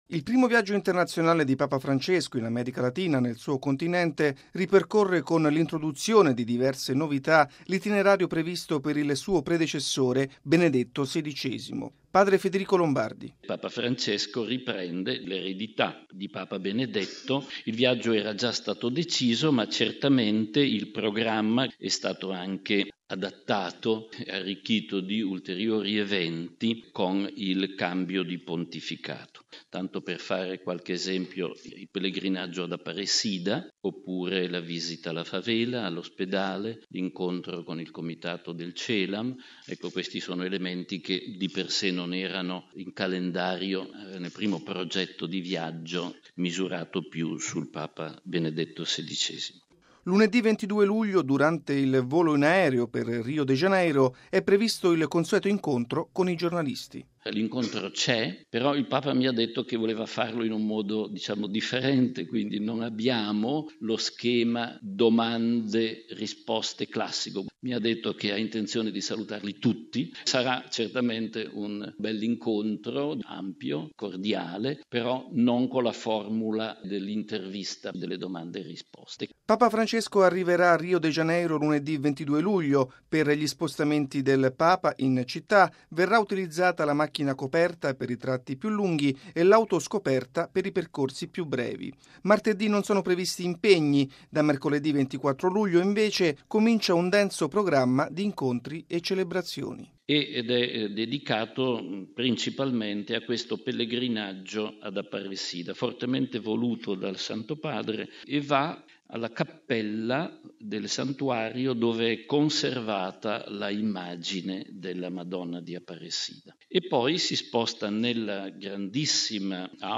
Logo 50Radiogiornale Radio Vaticana